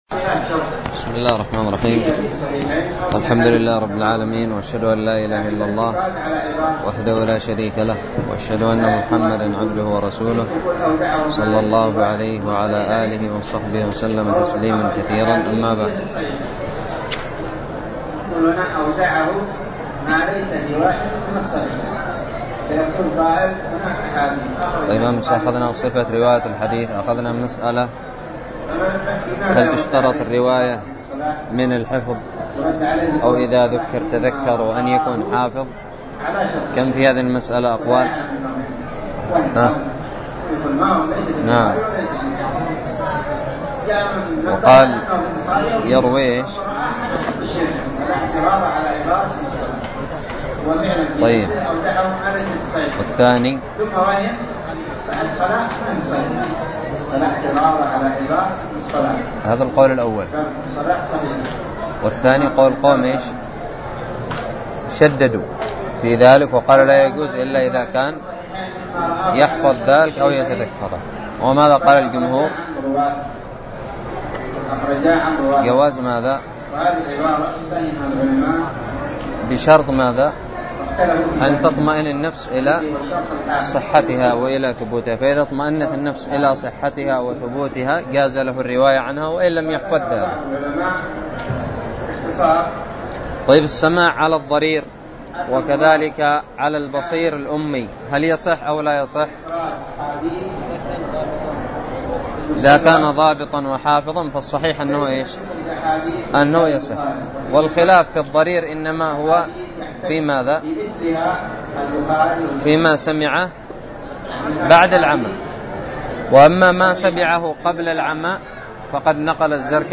الدرس الثامن والثلاثون من شرح كتاب الباعث الحثيث
ألقيت بدار الحديث السلفية للعلوم الشرعية بالضالع